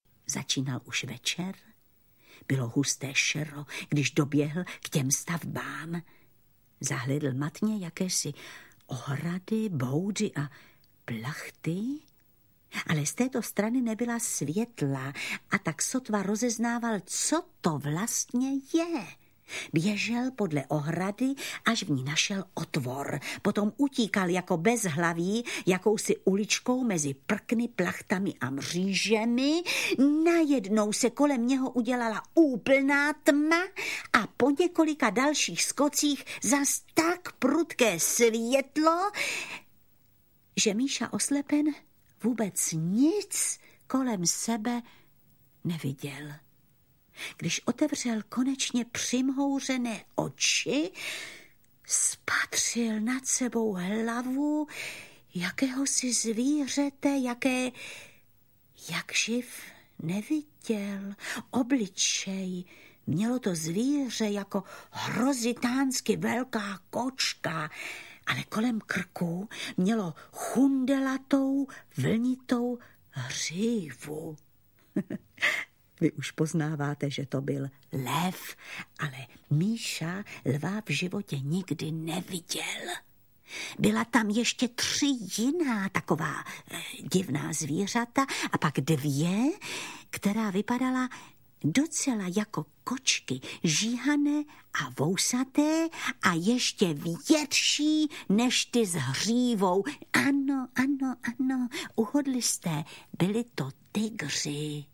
Míša Kulička v cirkuse audiokniha
Audio kniha
Ukázka z knihy